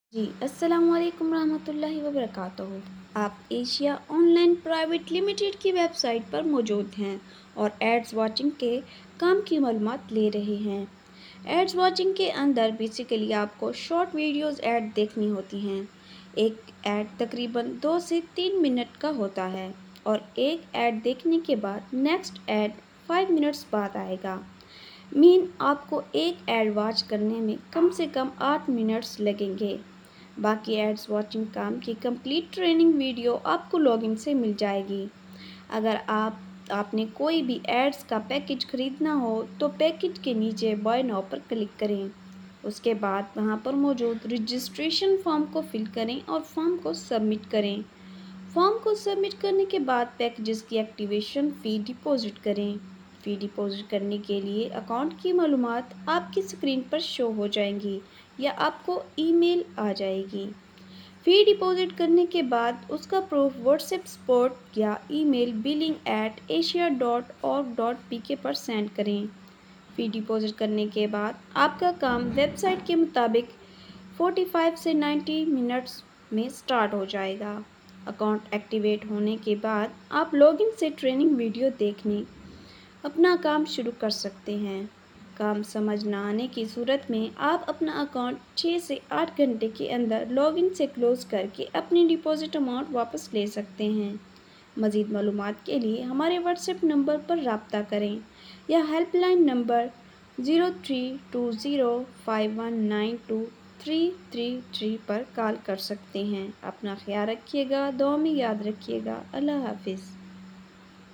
Listen This Voice Note Complete to Understand Ads Work
Ads-Watching-work-basic-info-Voice-_-Asia-Online-Private-Limited.mp3